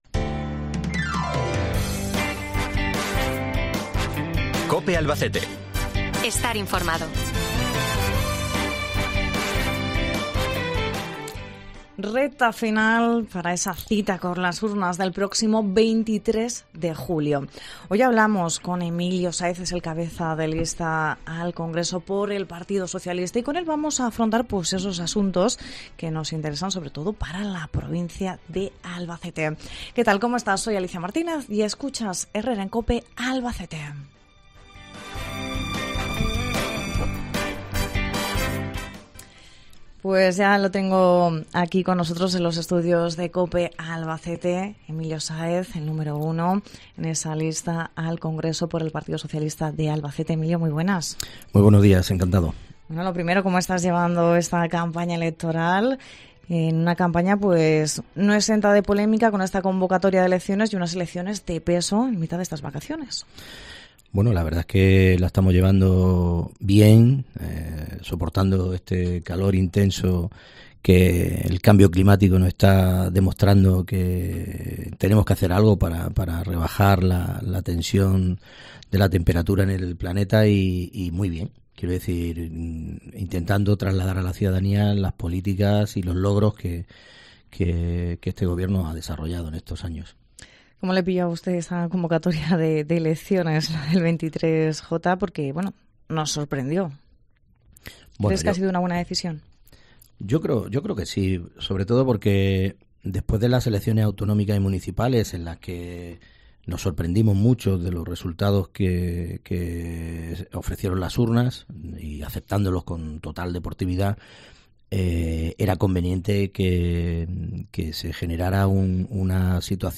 Hoy hablamos con Emilio Sáez, cabeza de lista al Congreso por el PSOE en Albacete, para hablar de las propuestas de la formación liderada por el presidente del Gobierno, Pedro Sánchez, en materia de infraestructuras y otros asuntos importantes para Albacete y la Comunidad Autónoma.